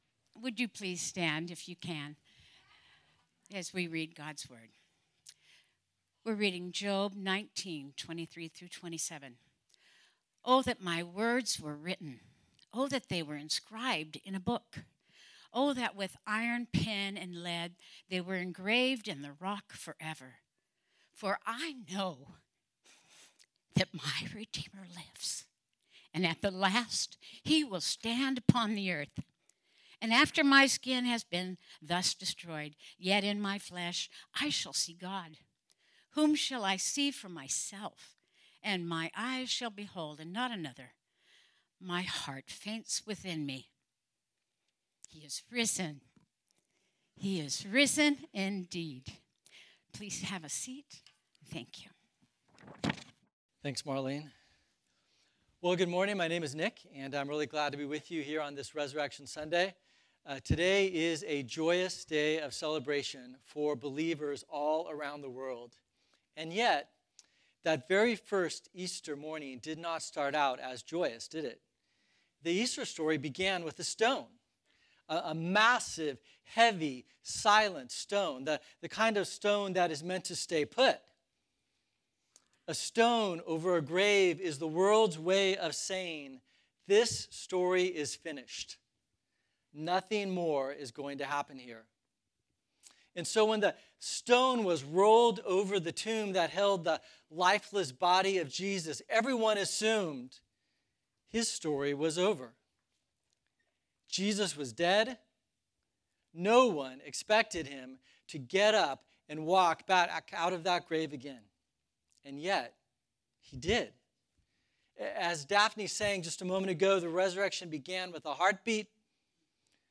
Type: Sermon